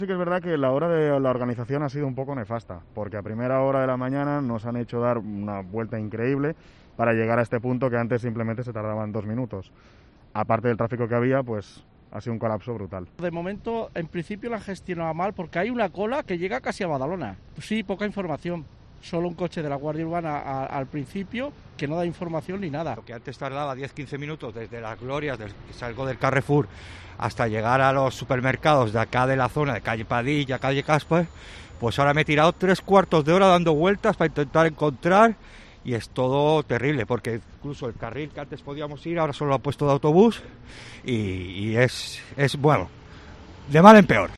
Primeros testimonios de conductores en el primer día laborable con el túnel de Glorias en funcionamiento